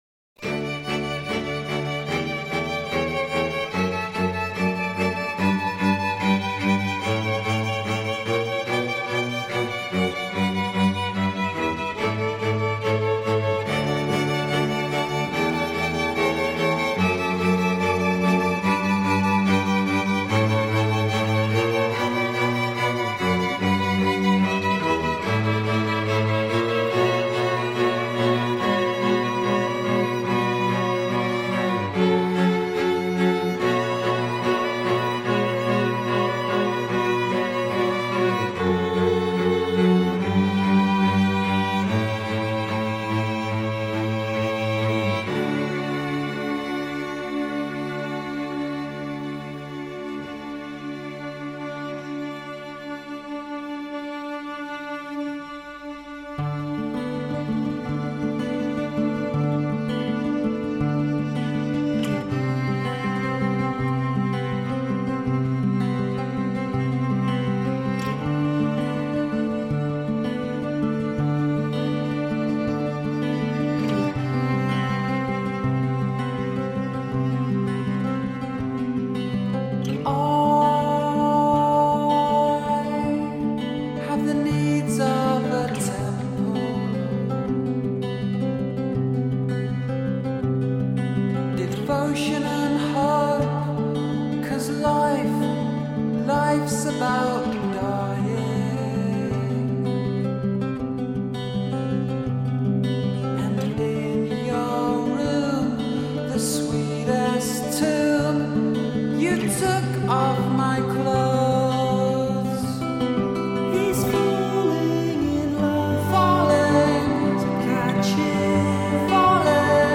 e poi membro di svariate altre importanti band indiepop.
Un mondo quieto e malinconico.